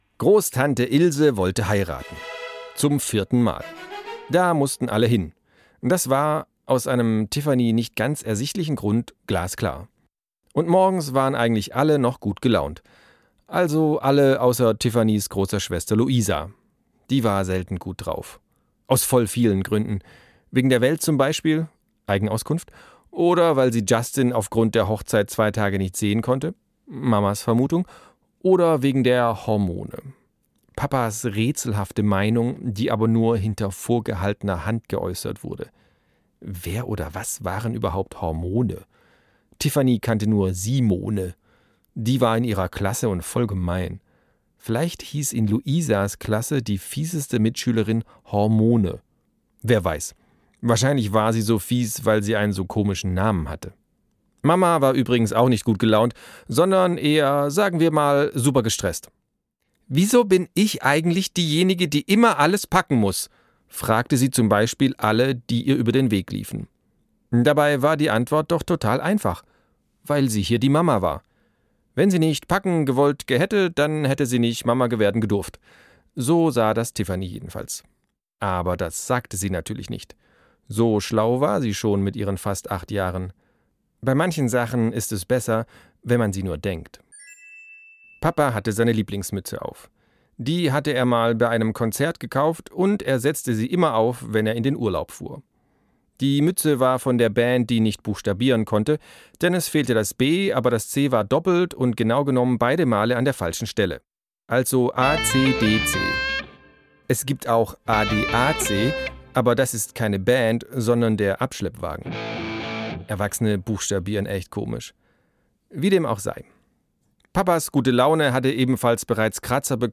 Sprecher Marc-Uwe Kling